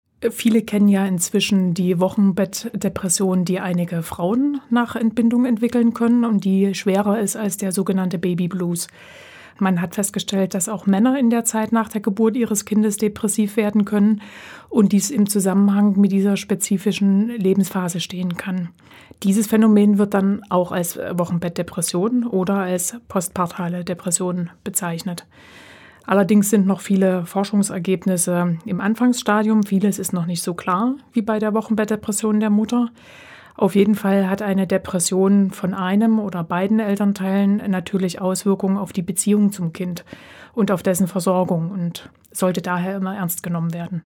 Radio O-Töne